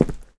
step9.wav